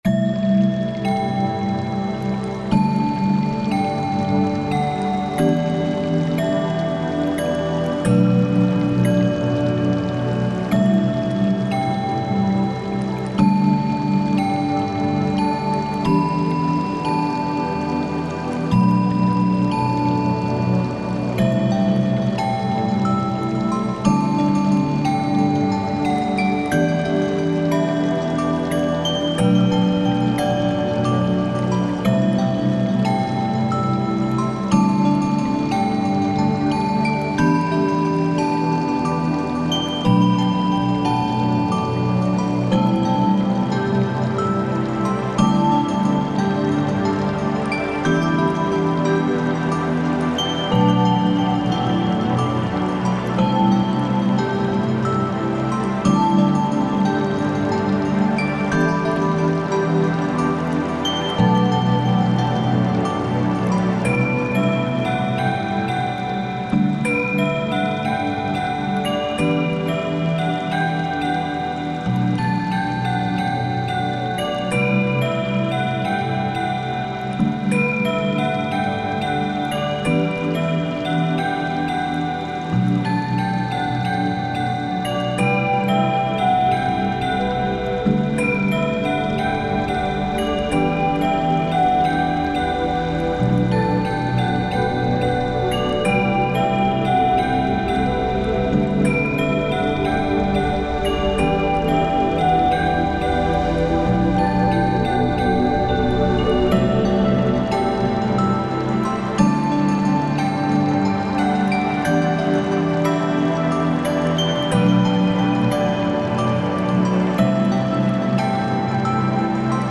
タグ: フィールド楽曲 リラックス/睡眠 幻想的 海/水辺 コメント: 水面に映る月をイメージした楽曲。